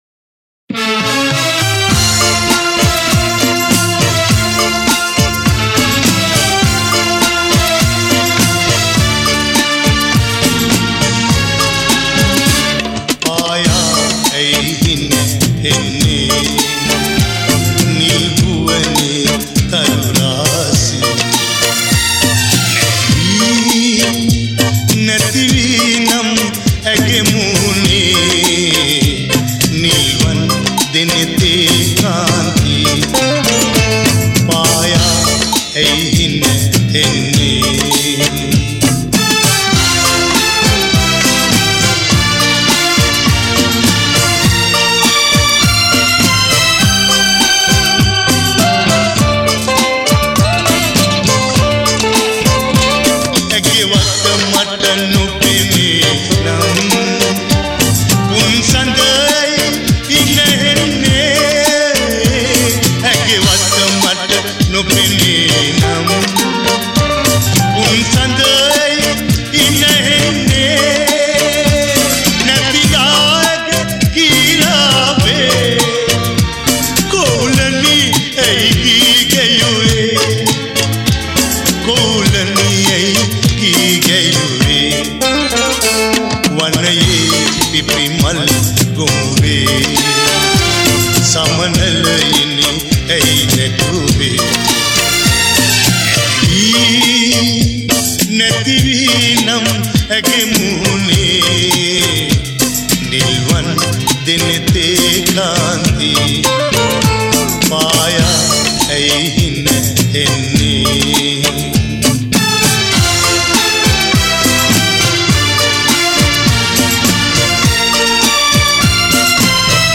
හින්දි තනුවක් ඇසුරින්